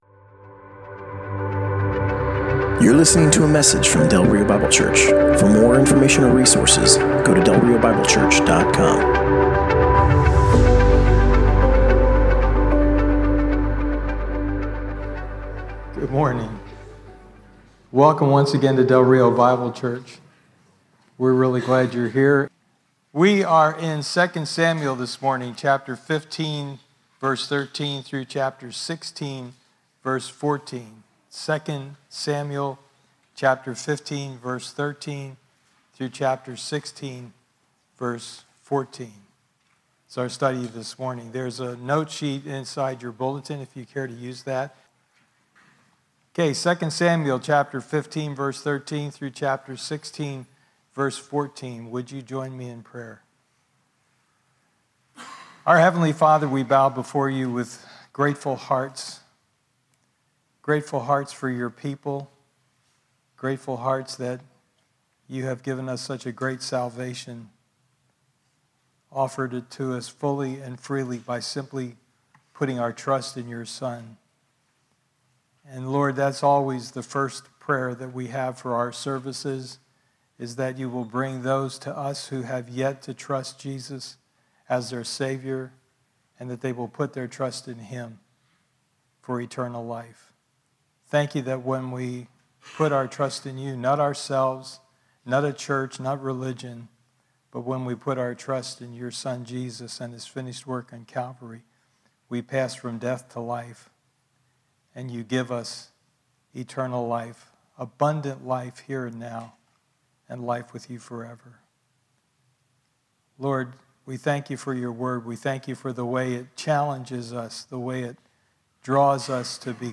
Passage: 2 Samuel 15:13 - 16:14 Service Type: Sunday Morning